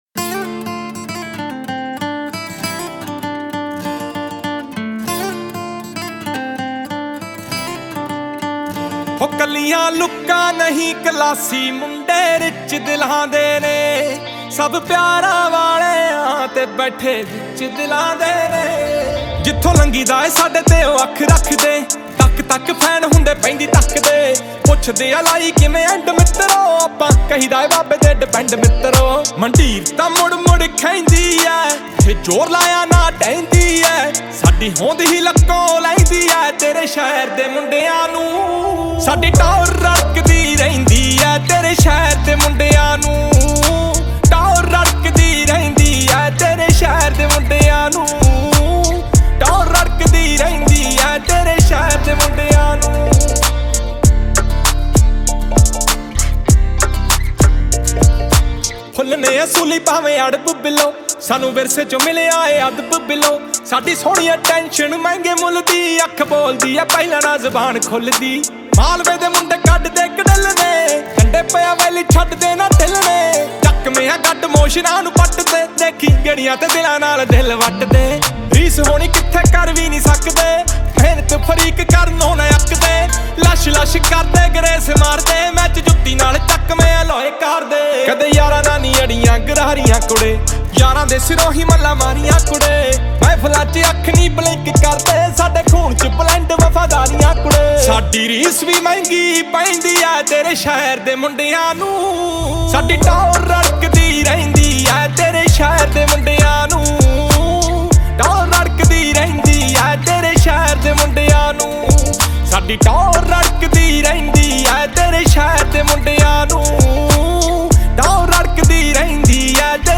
Punjabi Music